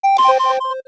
StopRecording.wav